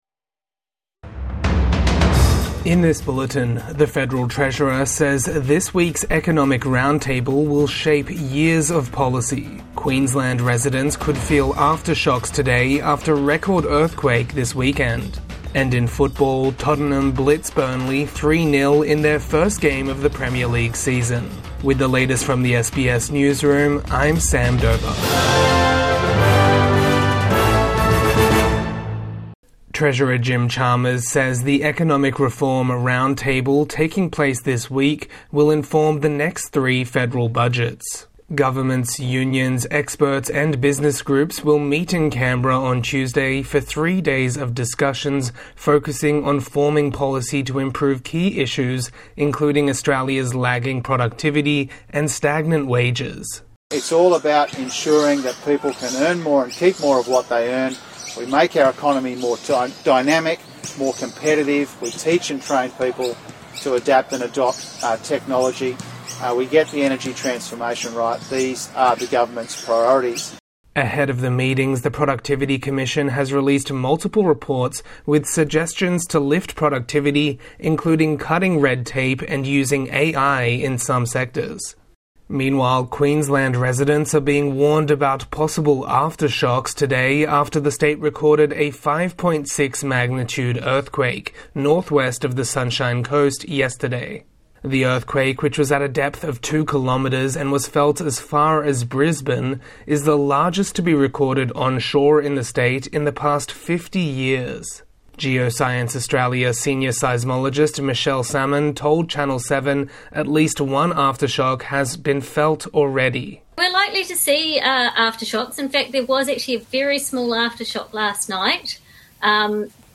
Economic roundtable to tackle stagnant wages and productivity | Midday News Bulletin 17 August 2025